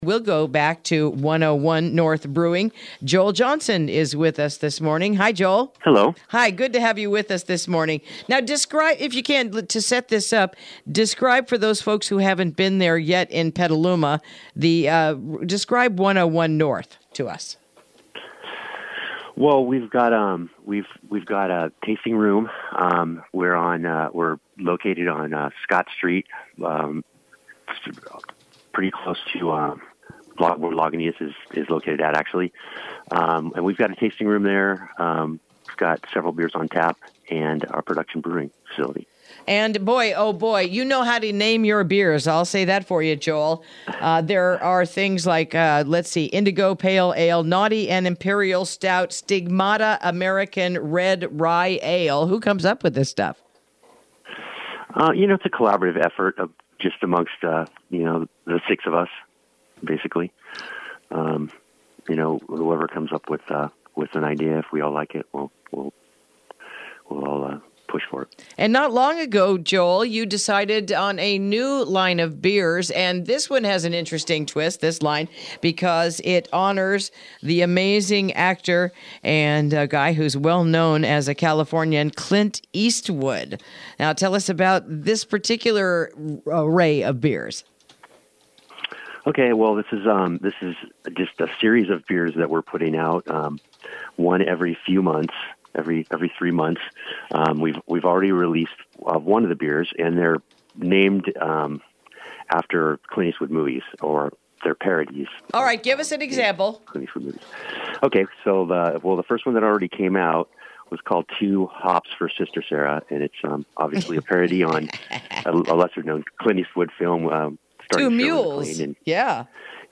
Interview: Beers Based on Clint Eastwood Movies?